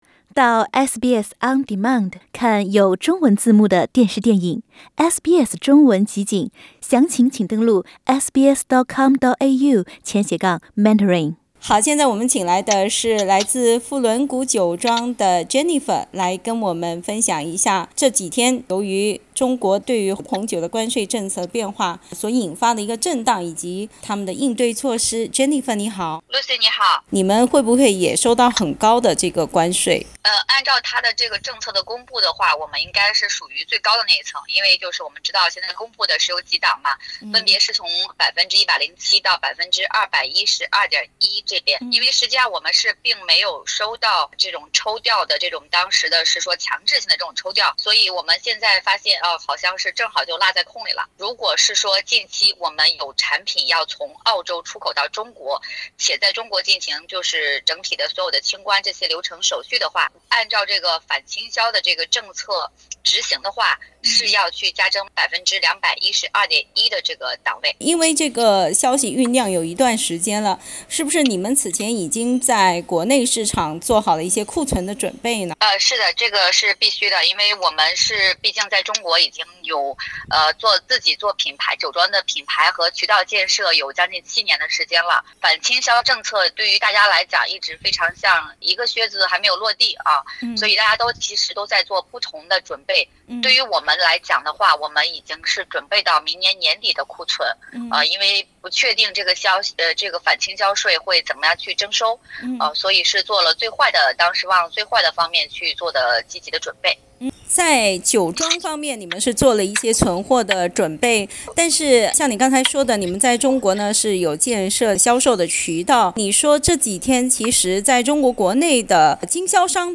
请关注我们的系列专访：靴子落下后的澳洲红酒华人企业何去何从？ 上周六，中国正式对澳洲红酒开征最高212.1%的关税。